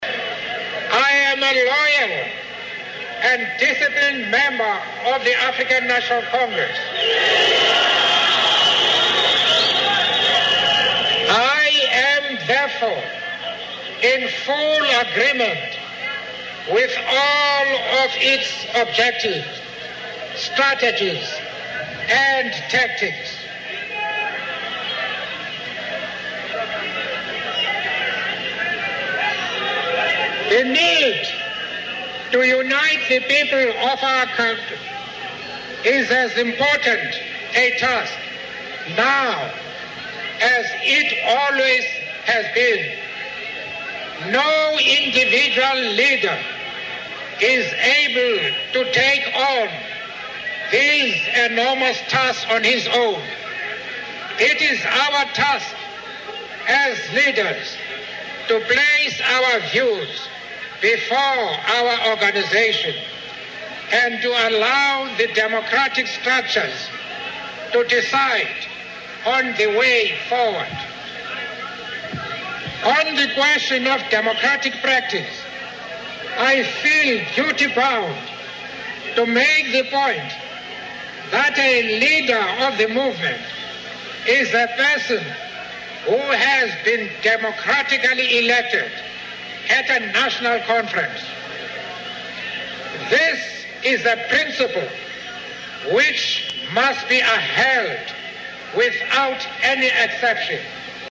名人励志英语演讲 第34期:为理想我愿献出生命(8) 听力文件下载—在线英语听力室